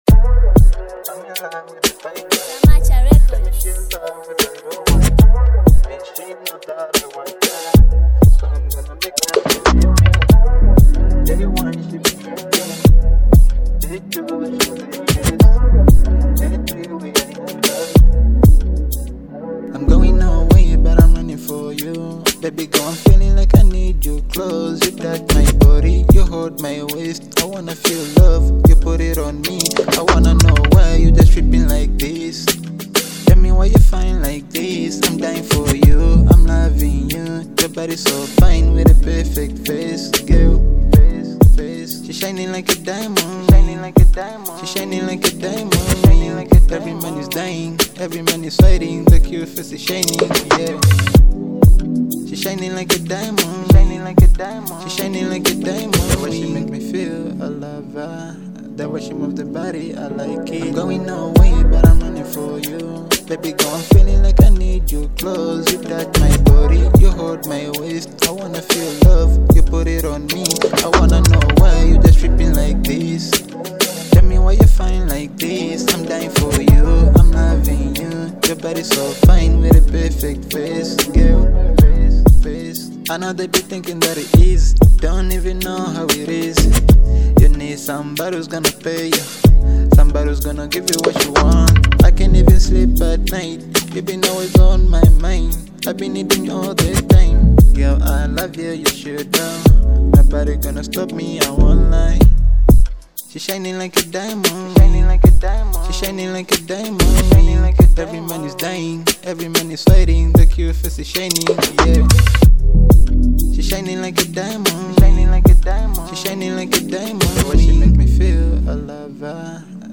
a smooth and uplifting sound